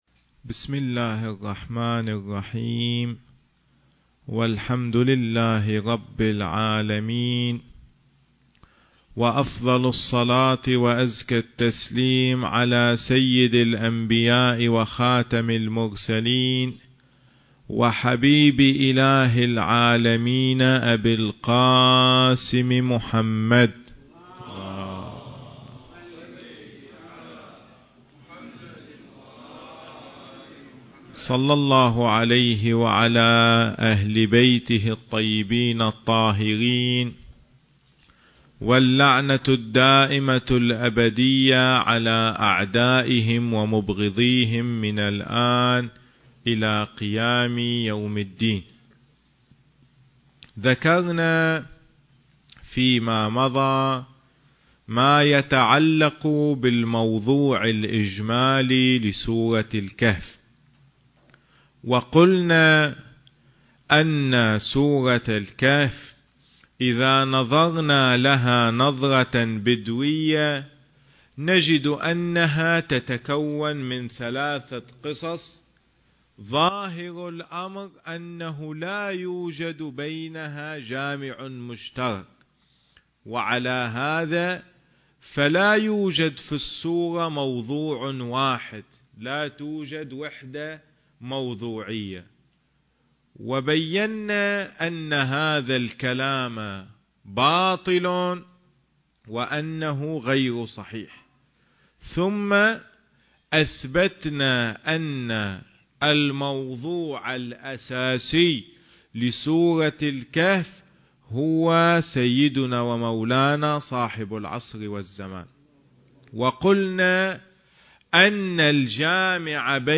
سلسلة: الإمام الحجة (عجّل الله فرجه) في سورة الكهف (2) حديث الجمعة التاريخ: 2015